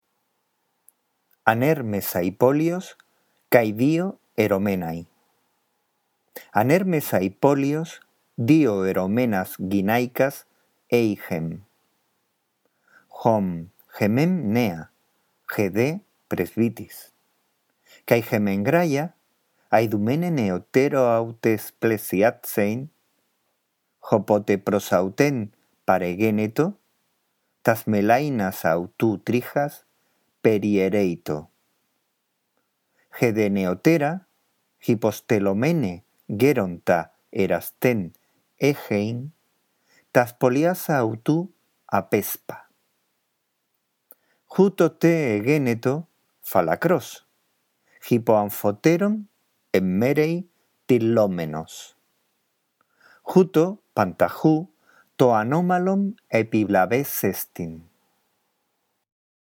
La audición de este archivo te guiará en la lectura del texto griego